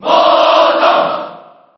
File:Morton Koopa Cheer JP SSB4.ogg